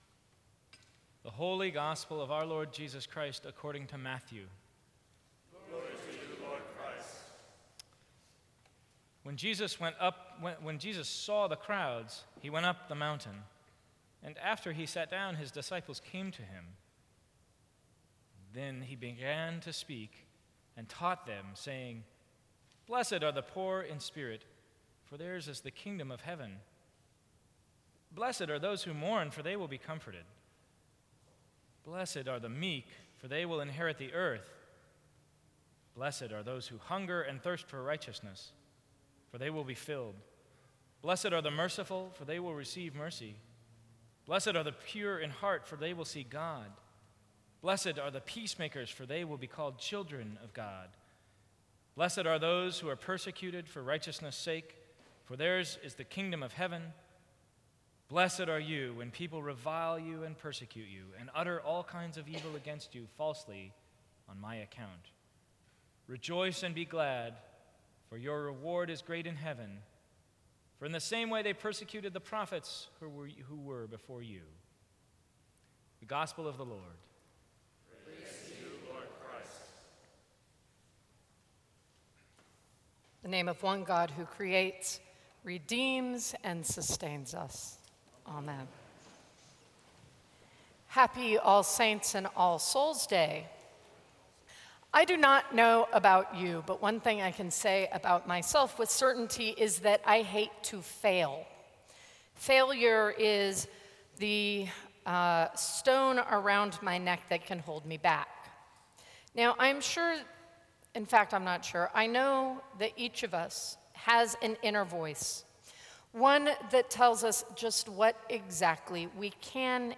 Sermons from St. Cross Episcopal Church November 2, 2014.